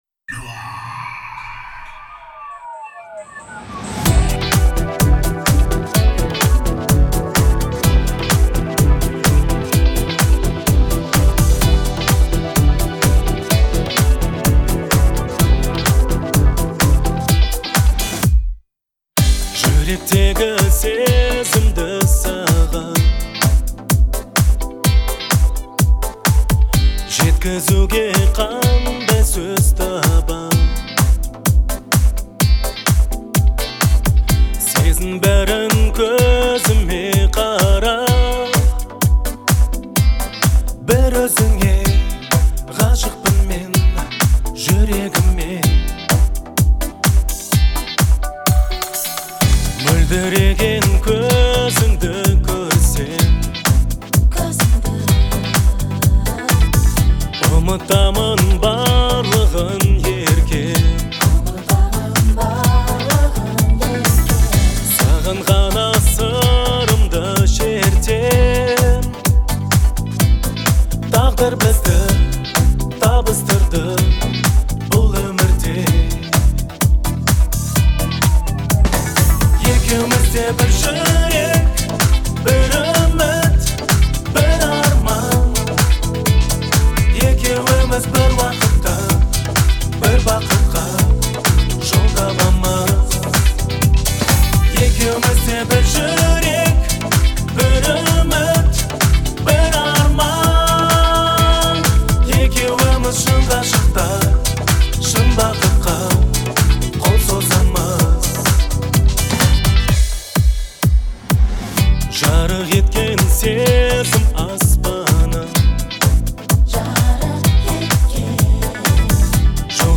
это трек в жанре поп